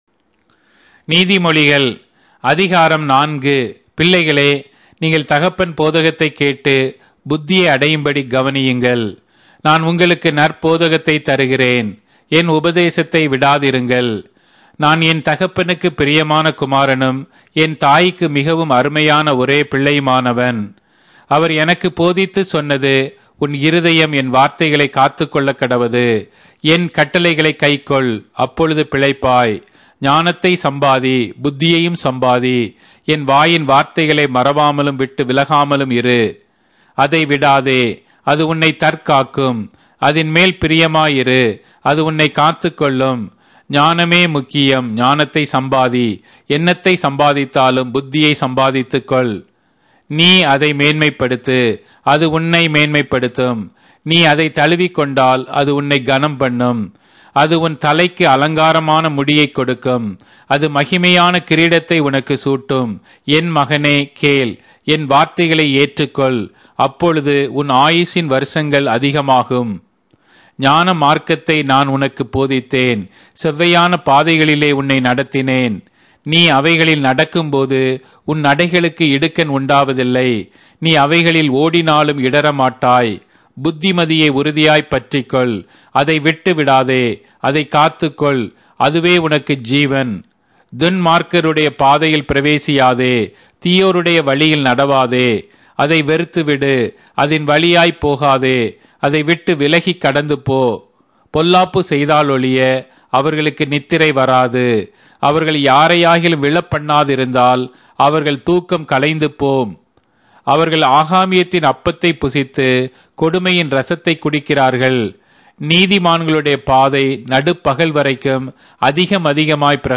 Tamil Audio Bible - Proverbs 13 in Gnterp bible version